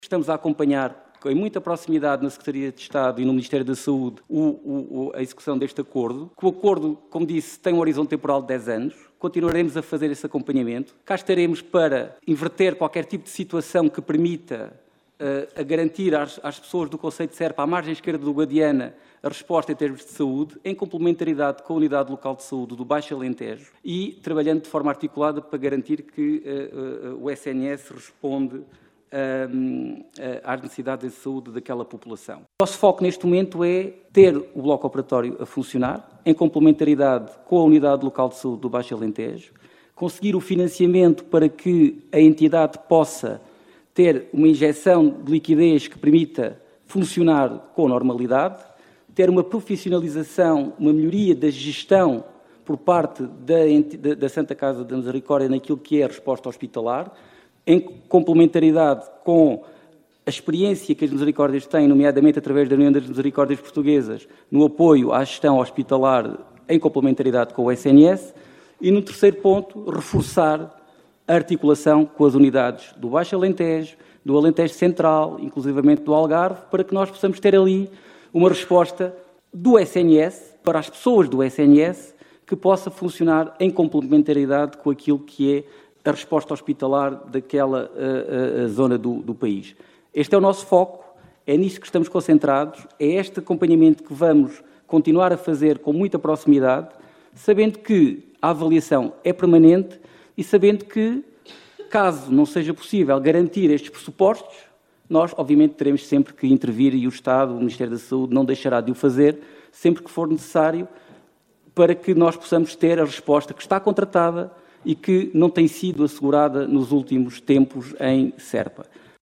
Realizou-se no passado dia 13 de Dezembro na Assembleia da República a audição ao secretário de estado da saúde, Ricardo Mestre, sobre o acordo de cooperação entre a Santa Casa da Misericórdia de Serpa e o Ministério da Saúde para a gestão do Hospital São Paulo em Serpa.